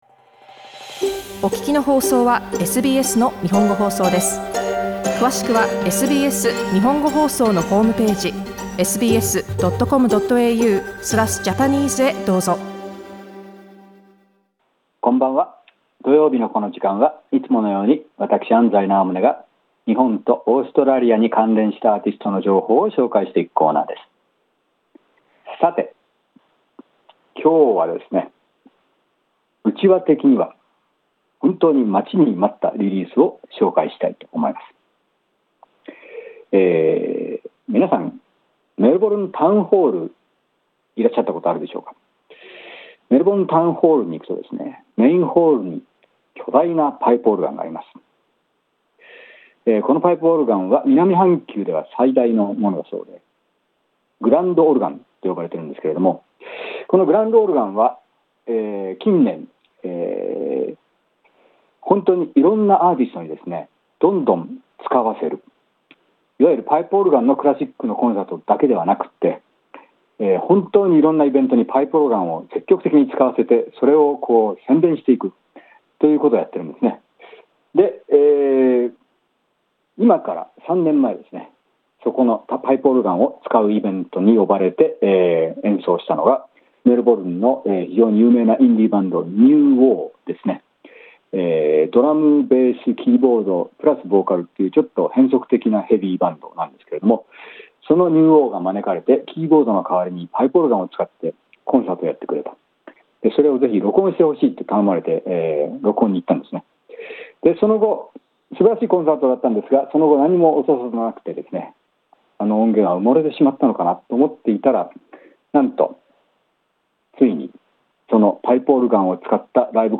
南半球最大と言われるメルボルン・タウンホールのパイプオルガンを使ったスペシャル・ギグです。